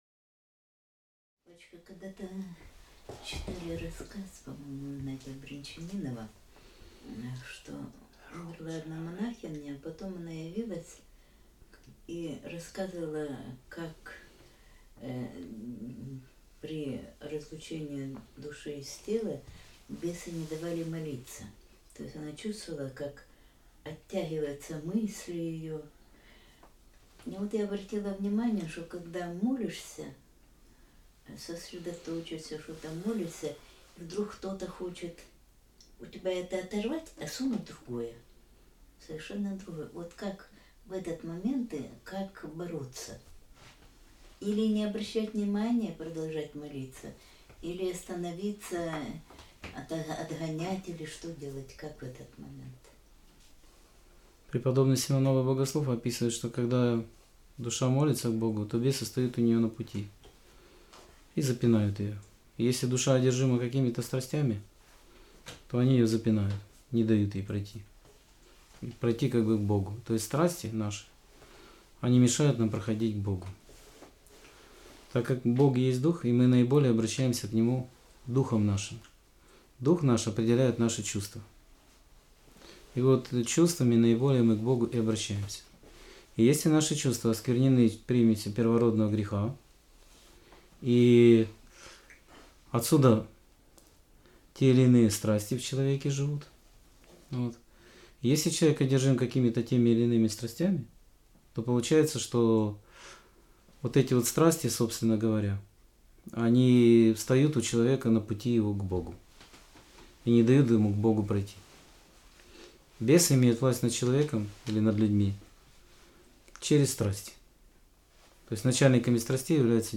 Скайп-беседа 26.03.2016